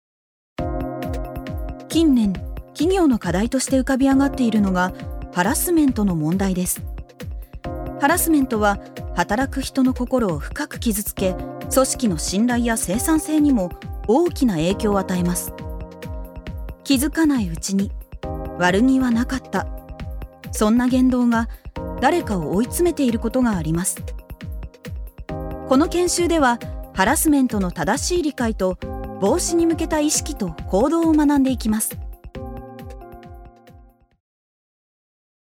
ジュニア：女性
ナレーション２